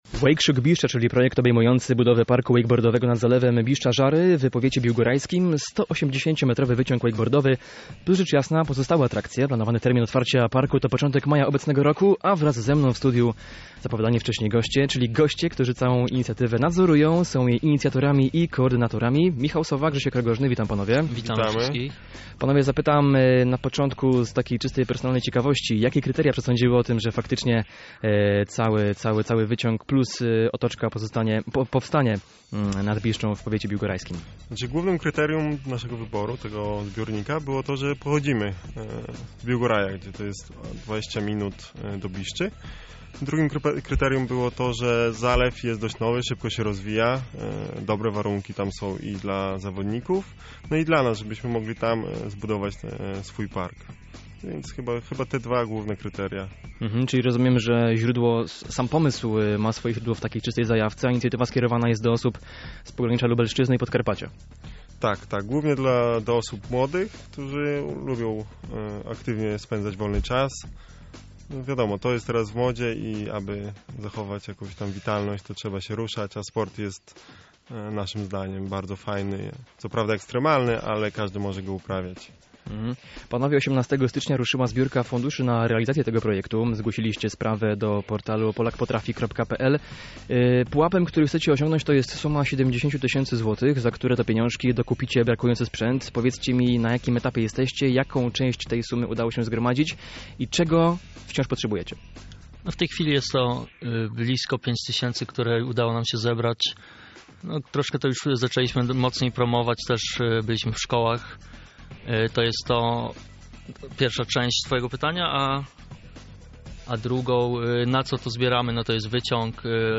Poniżej rozmowa